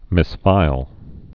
(mĭs-fīl)